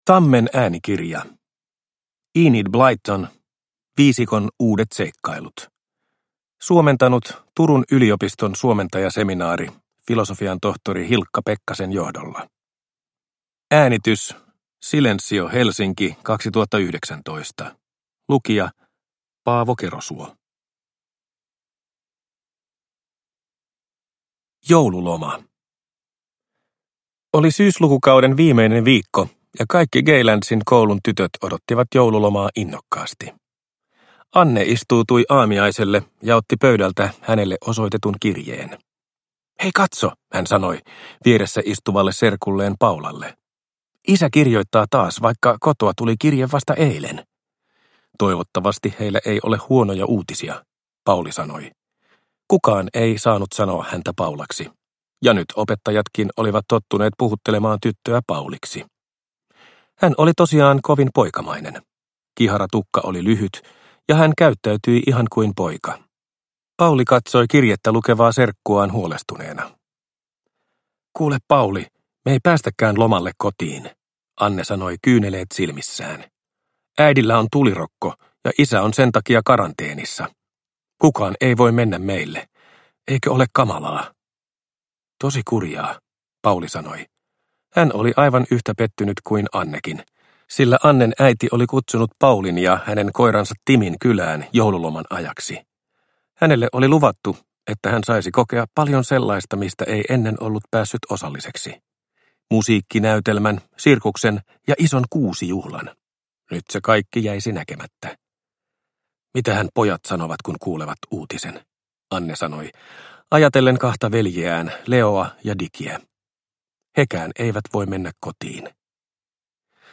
Viisikon uudet seikkailut – Ljudbok – Laddas ner